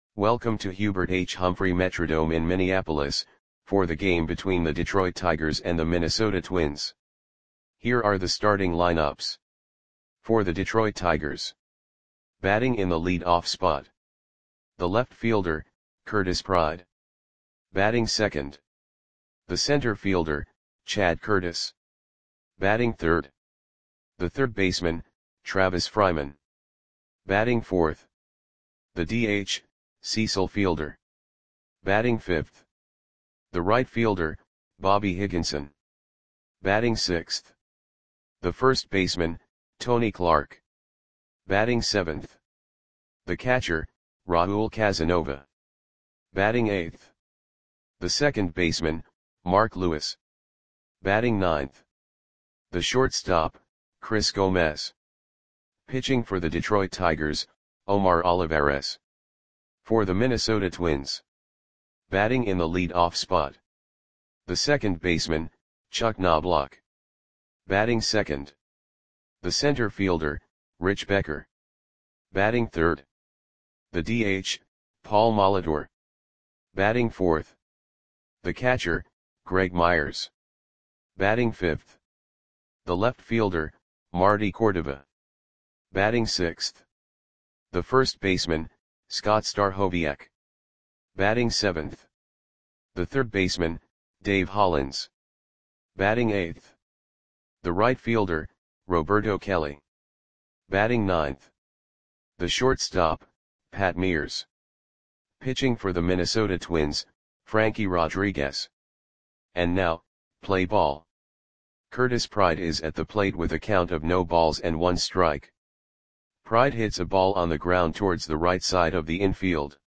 Audio Play-by-Play for Minnesota Twins on June 15, 1996
Click the button below to listen to the audio play-by-play.